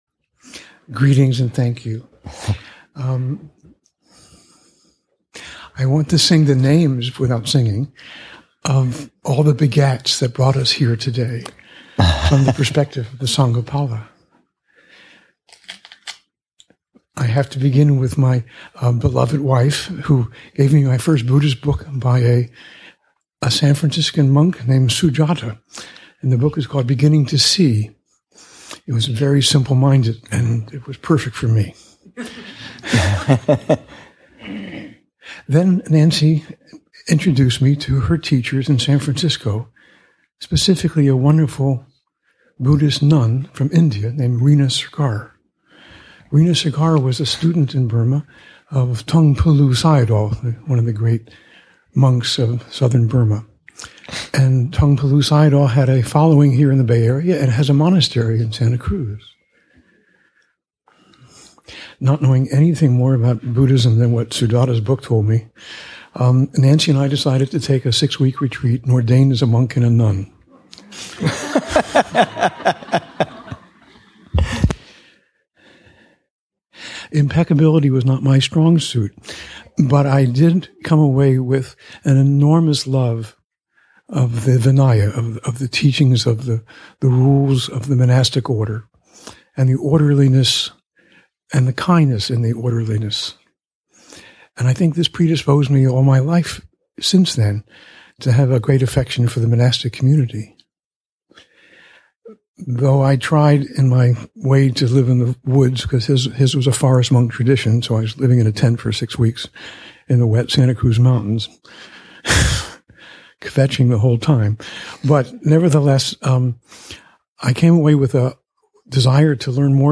Abhayagiri's 20th Anniversary, Session 7 – Jun. 4, 2016